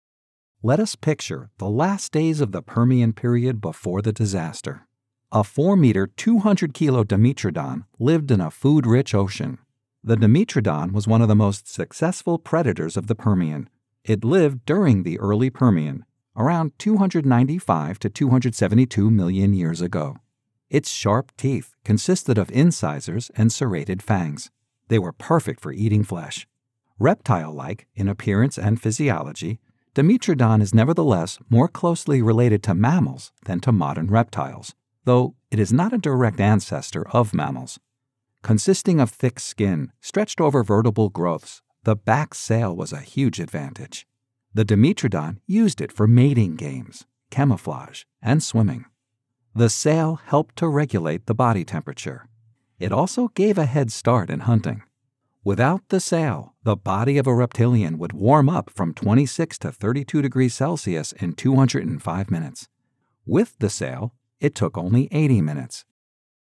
Nature Segment as part of a documentary series
New York "Brooklynese"
Middle Aged